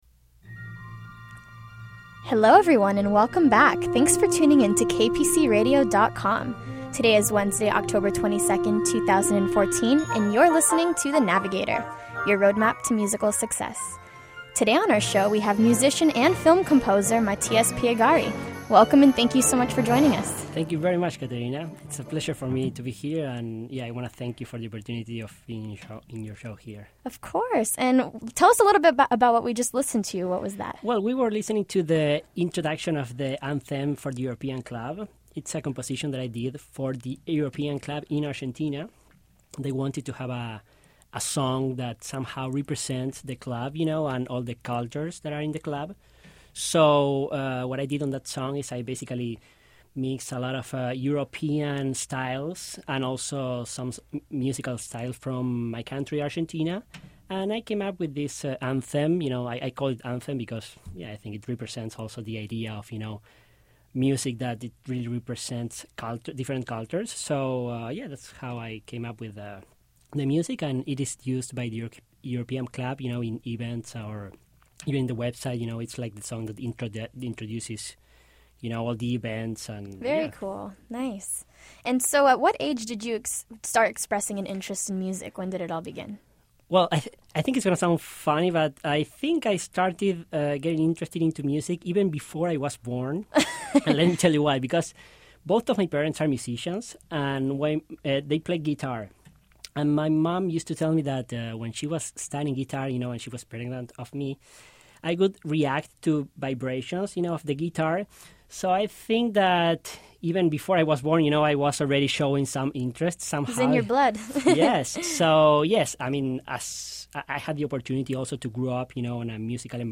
The Navigator is a weekly talk show